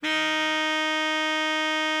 bari_sax_063.wav